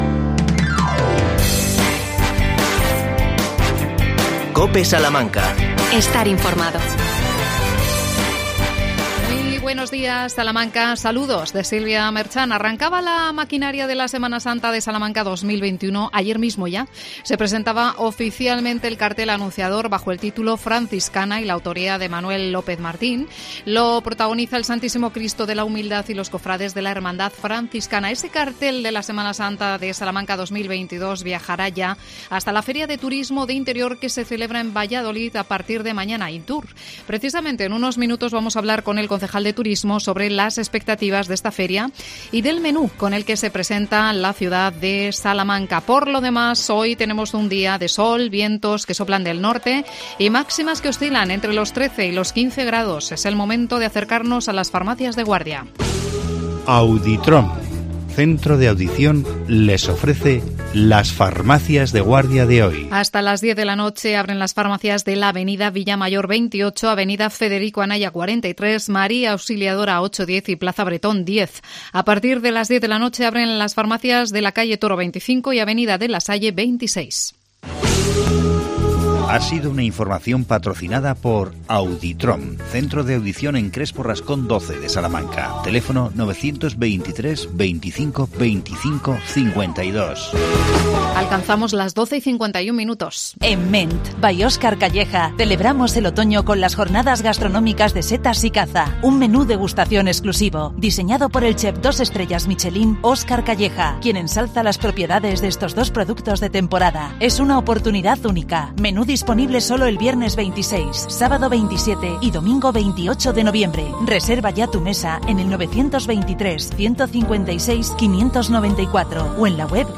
AUDIO: Salamanca estará mañana en INTUR. Entrevistamos al concejal de Turismo Fernando Castaño.